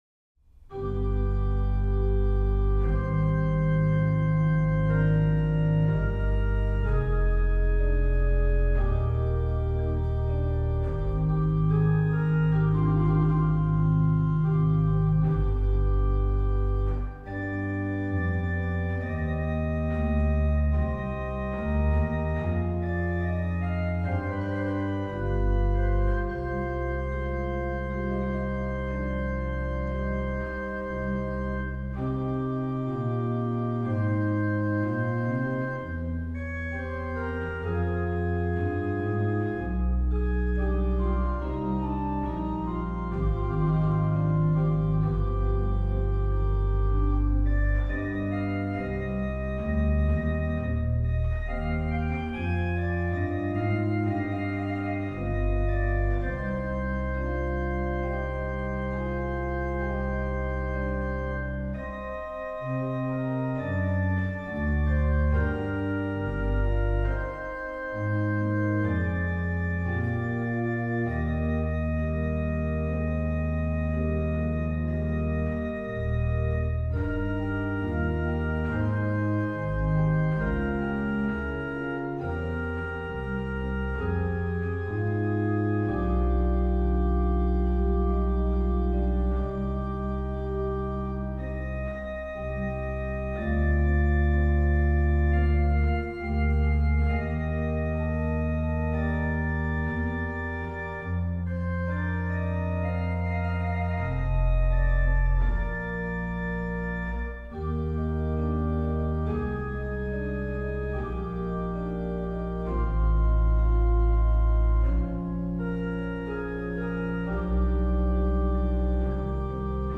Subtitle   à 2 claviers et pédale
Registration   rh: MAN: Bor8, Gms4, Nas3
lh: POS: Lged8, Fl4
PED: Sub16, Qnt16, Bor8